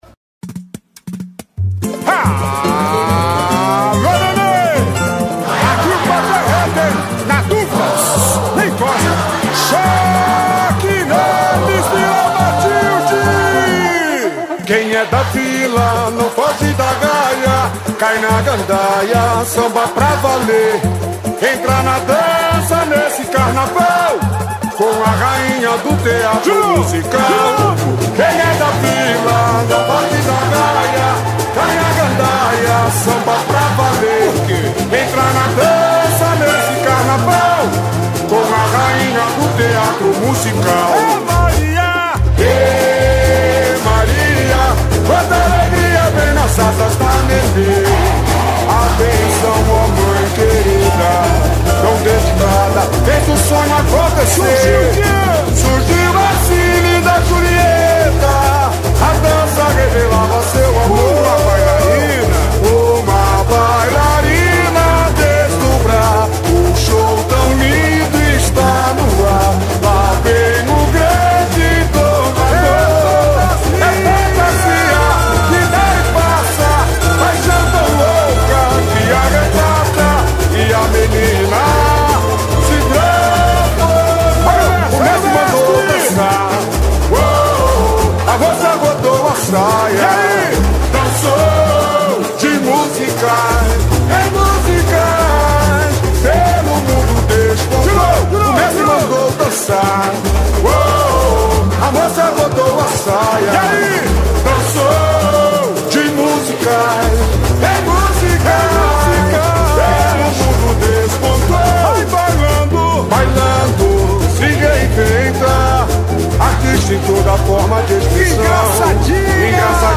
Puxador: